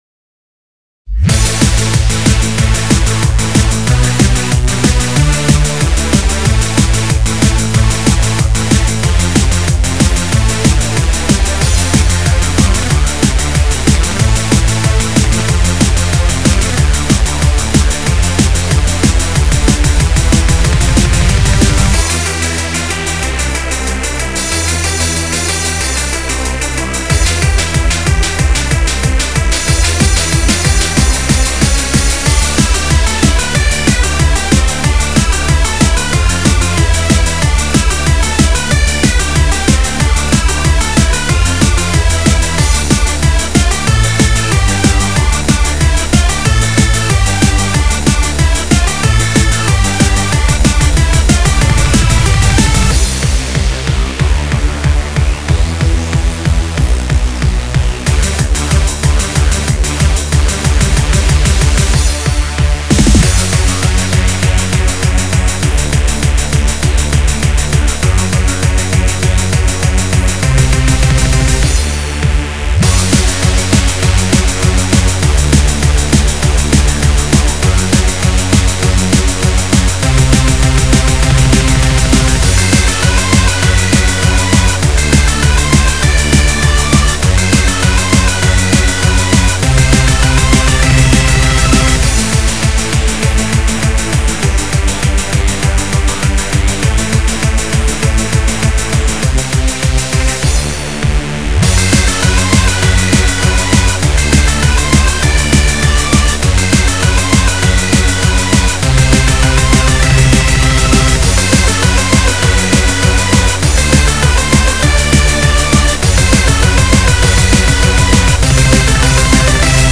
BPM186-372
Audio QualityPerfect (High Quality)
Genre: Freeform Hardcore